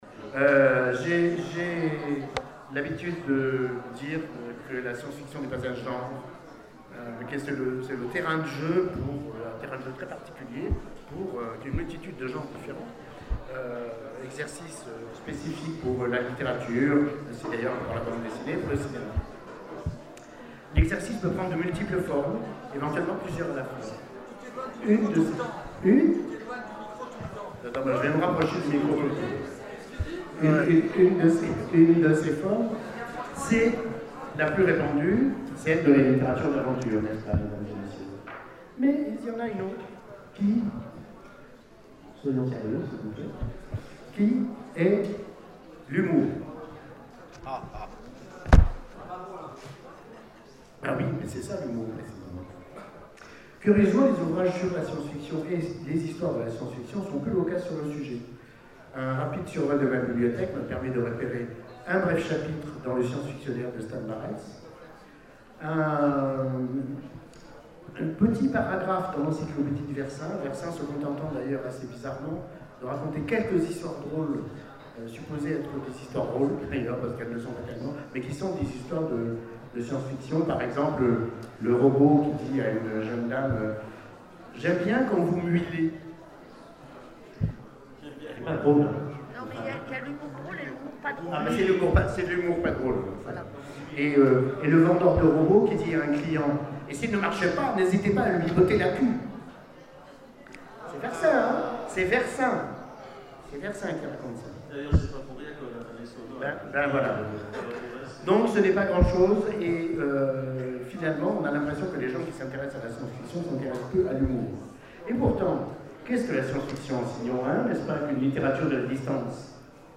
Convention 2012 : Conférence Humour et science fiction